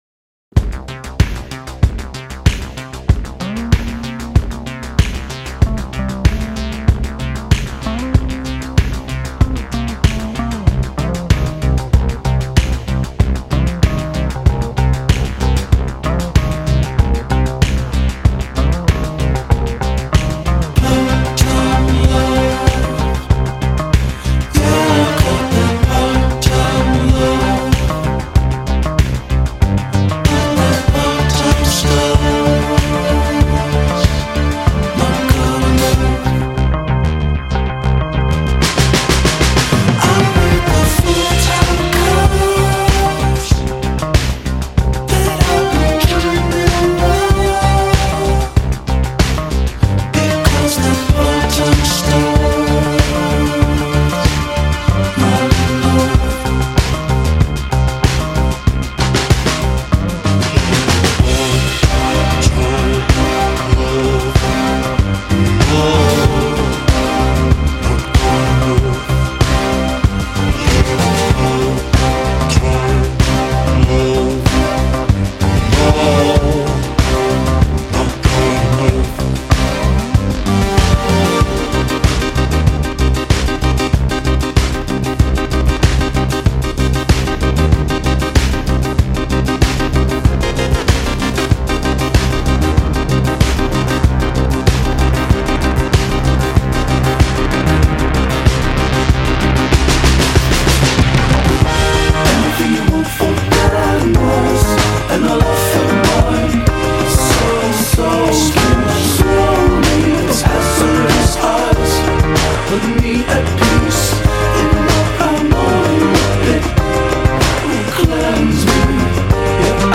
continues that trend of dark pop tradition.
distorted growls and pitch-shifted vocal lines